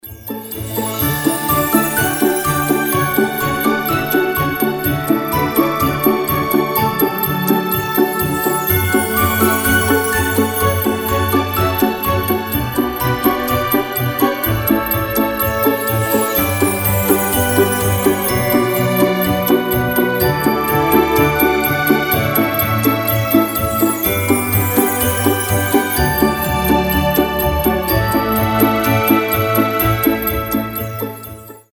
Красивая новогодняя музыка